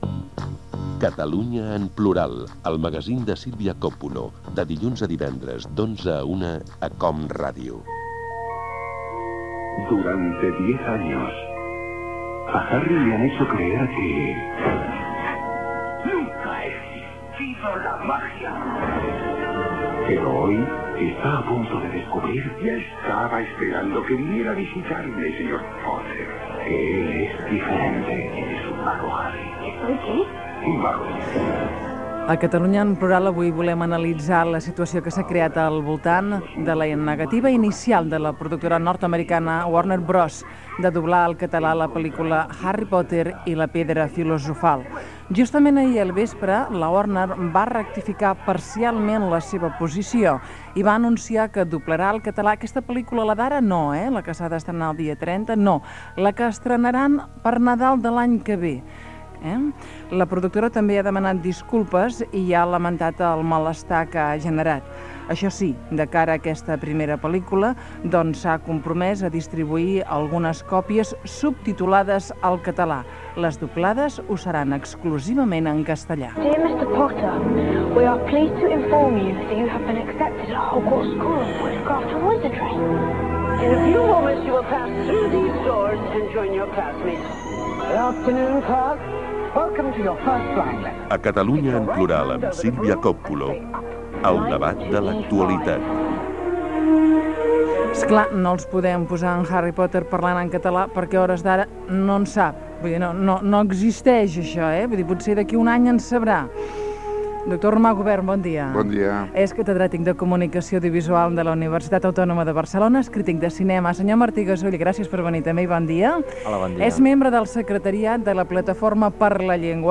Indicatiu del programa.
Info-entreteniment